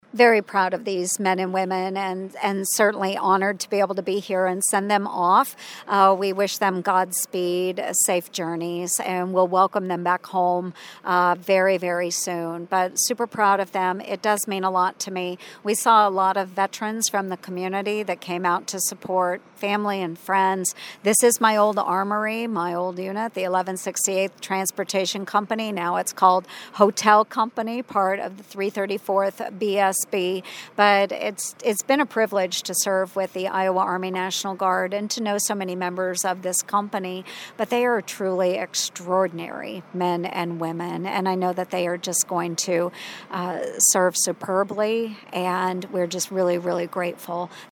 After about a 20 minute ceremony and greeting soldiers and their families, Senator Ernst talked exclusively with KSOM and KS 95 about coming home